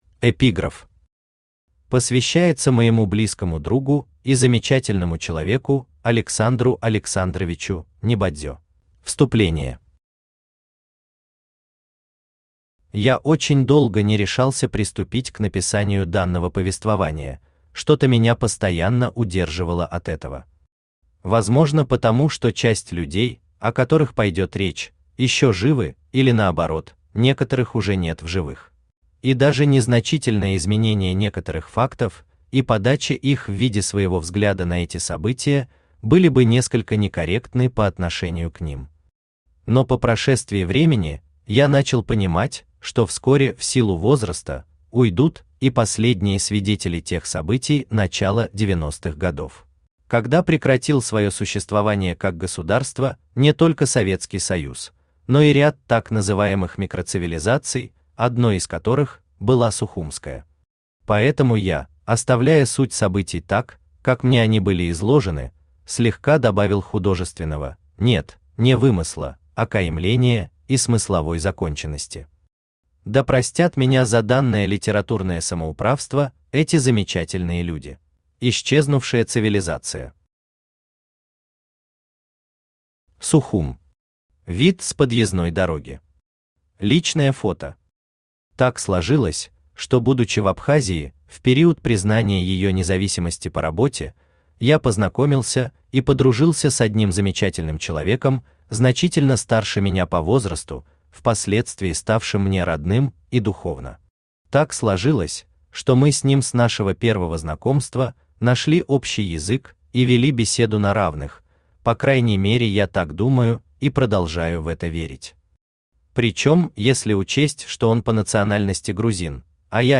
Аудиокнига Сухумские хроники | Библиотека аудиокниг
Aудиокнига Сухумские хроники Автор Валерий Муссаев Читает аудиокнигу Авточтец ЛитРес.